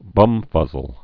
(bŭmfŭzəl)